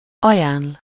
Ääntäminen
France: IPA: [pʁo.po.ze]